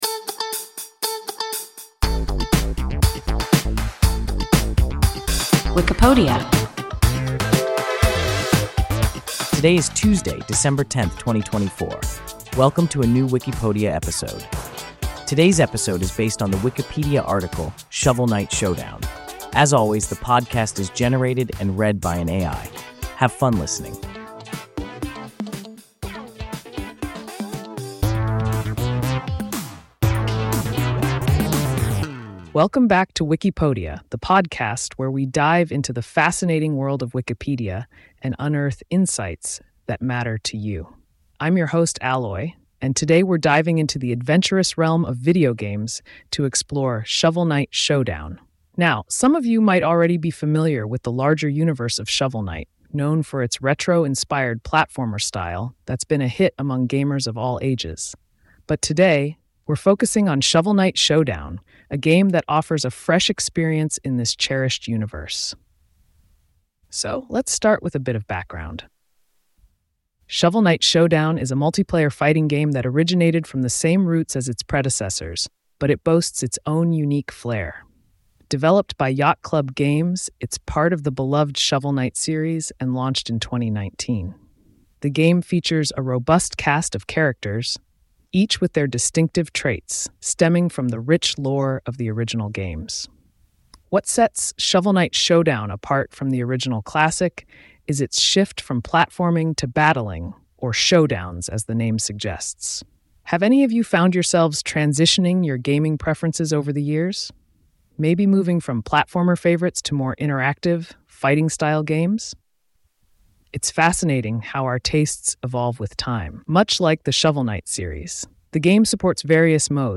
Shovel Knight Showdown – WIKIPODIA – ein KI Podcast